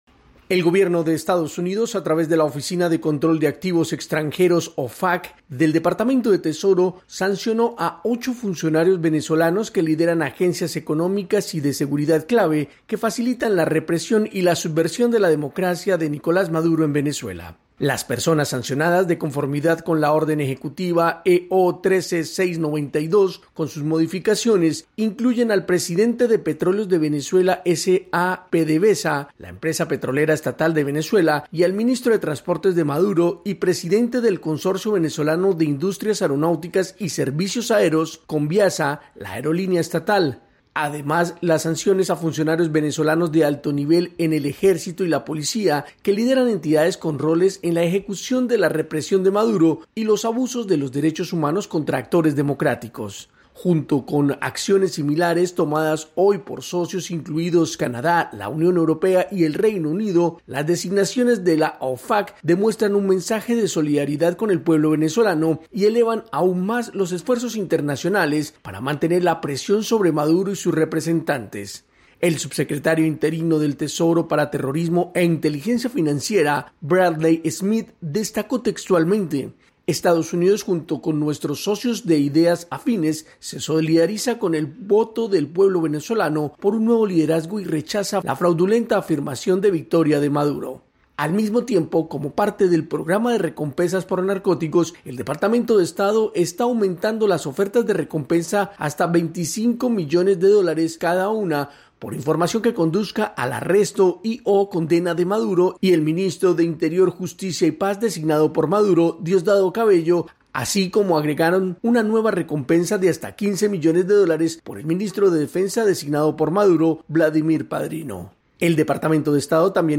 AudioNoticias
desde Washington, DC.